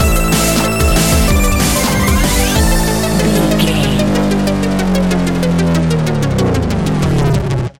Epic / Action
Fast paced
Aeolian/Minor
intense
energetic
driving
dark
aggressive
drum machine
electronic
sub bass
synth leads
synth bass